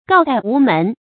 告貸無門 注音： ㄍㄠˋ ㄉㄞˋ ㄨˊ ㄇㄣˊ 讀音讀法： 意思解釋： 告貸：向別人借錢。